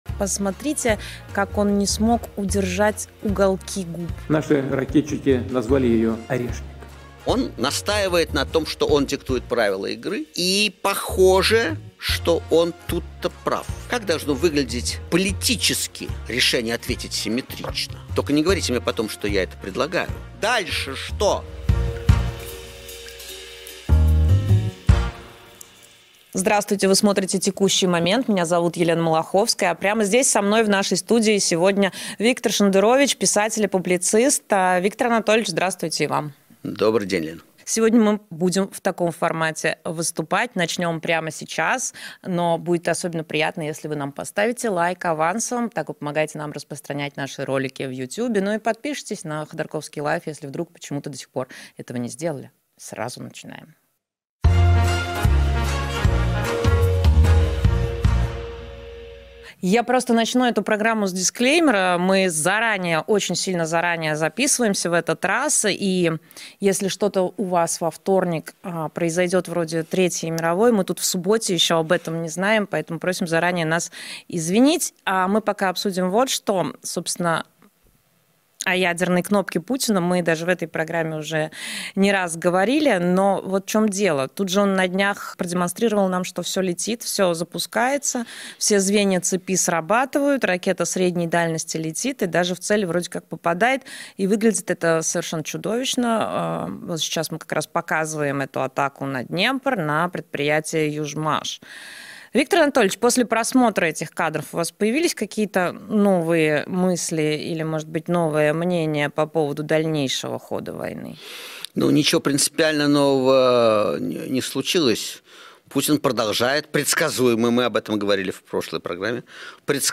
«Текущий момент» с Виктором Шендеровичем: Что изменил «Орешник». Ядерная угроза Путина и реакция Запада. Живая программа в студии